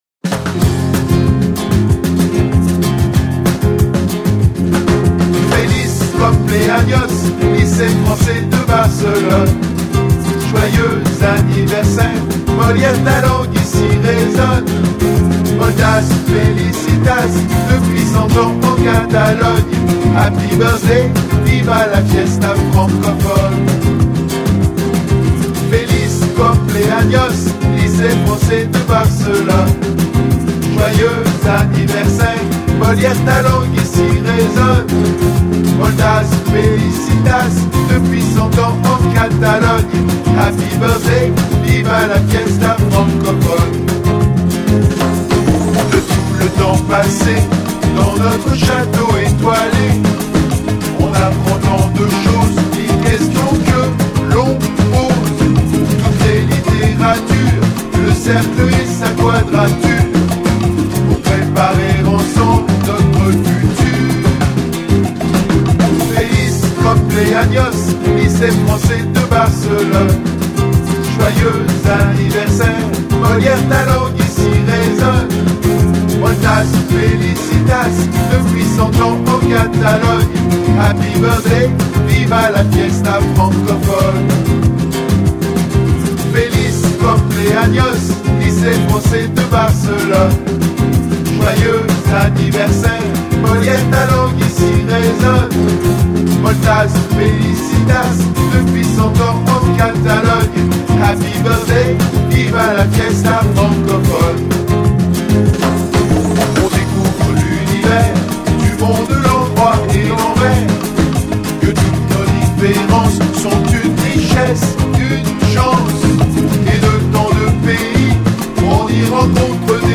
« Viva la fiesta francophone » et « Tout a changé rien a changé » ont retenti dans les couloirs de l’école et ont été chanté par toutes les classes de l’élémentaire lors de la fête du centenaire le 16 novembre !
VIVA-LA-FIESTA-FRANCOPHONE_f_minor.mp3